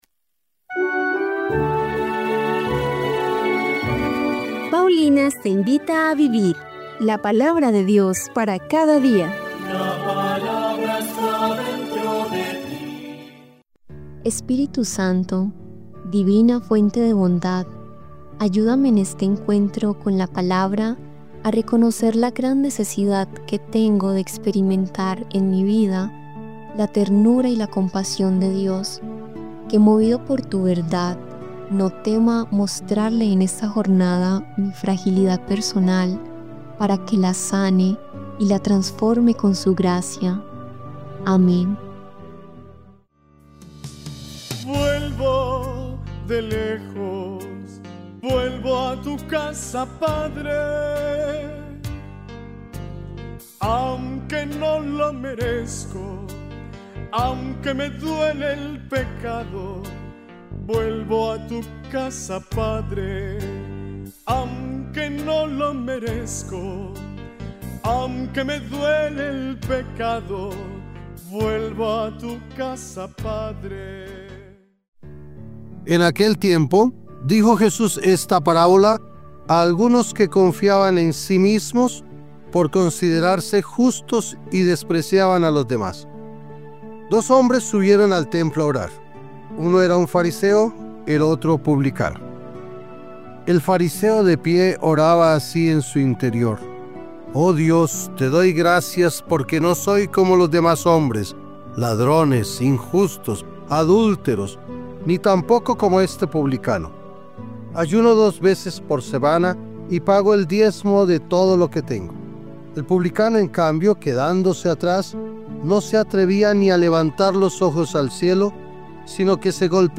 Lectura del libro de Isaías 52, 13 – 53, 12